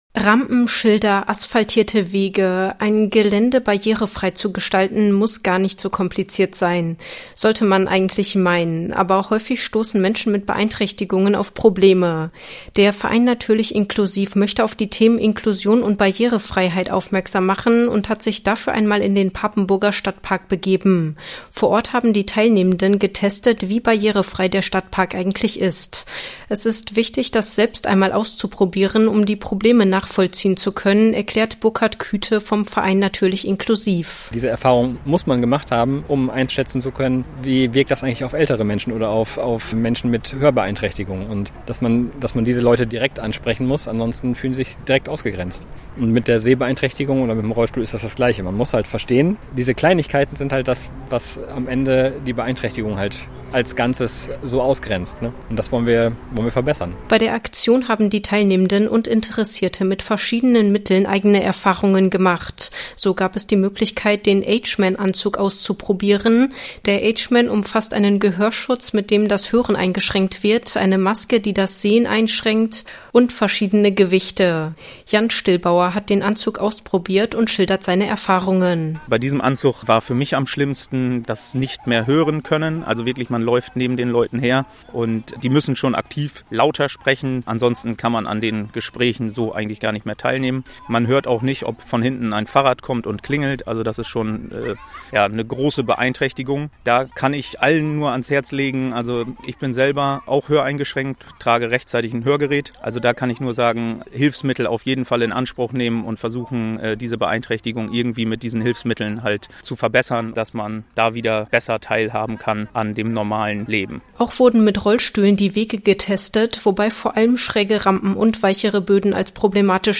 berichtet